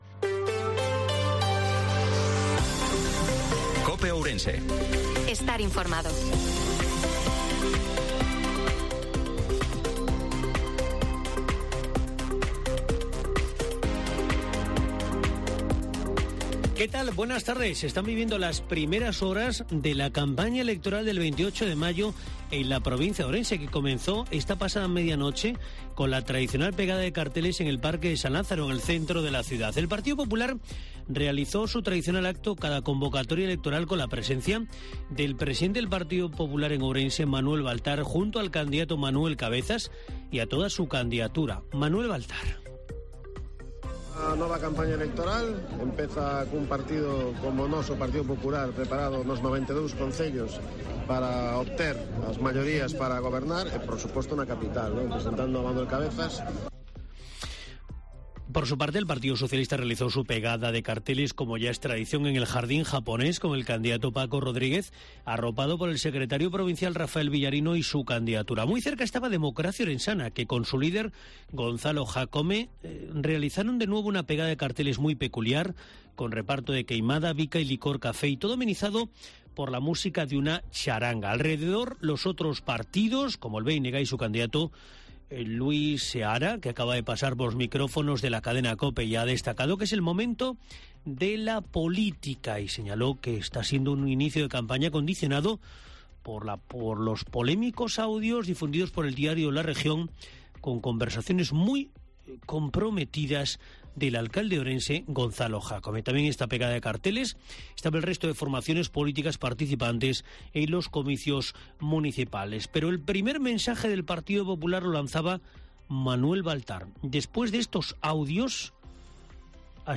INFORMATIVO MEDIODIA COPE OURENSE-12/05/2023